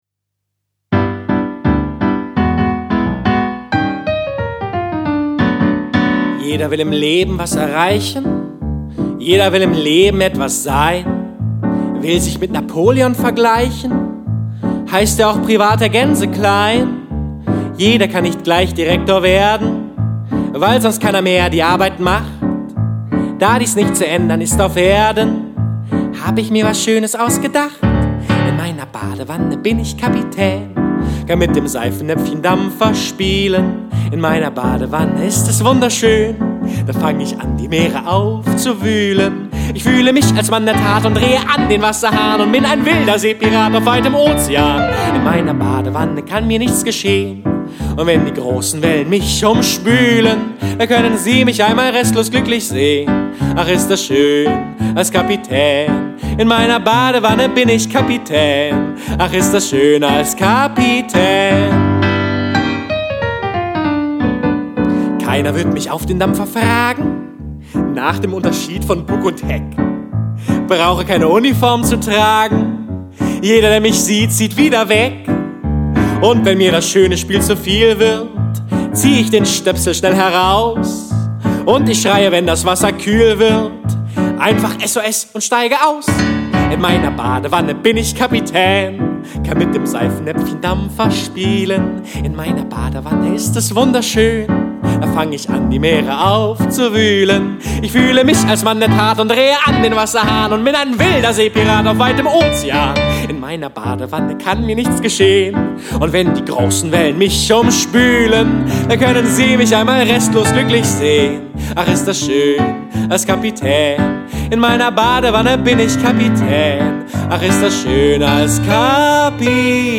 Der Sänger.
Der Pianist.